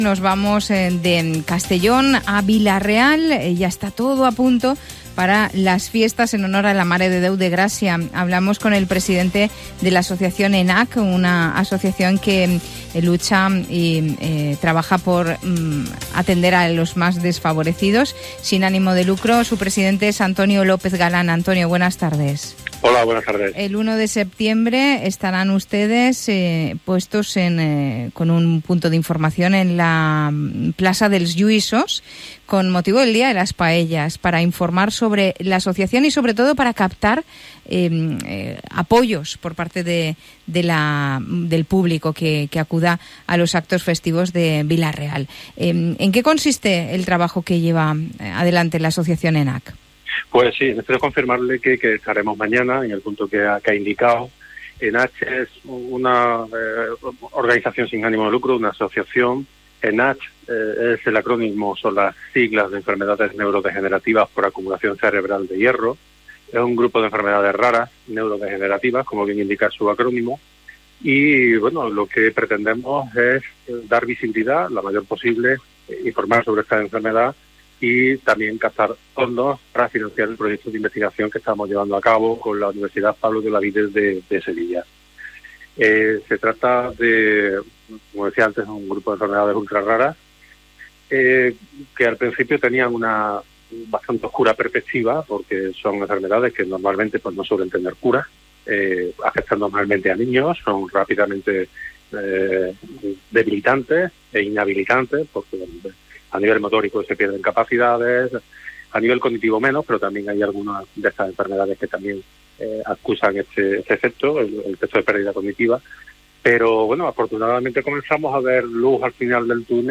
Audio de la entrevista.